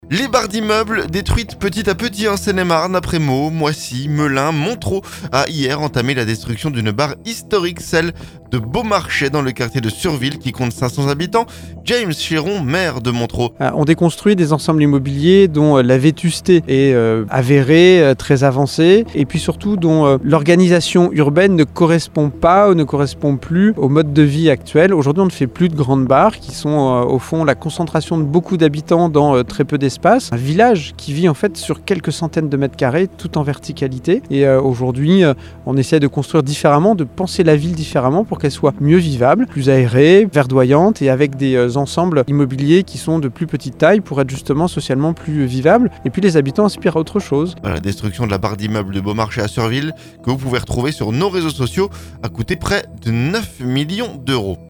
Montereau a entamé lundi la destruction d'une barre historique, celle de Beaumarchais dans le quartier de Surville qui compte 500 habitants. James Chéron, maire de Montereau.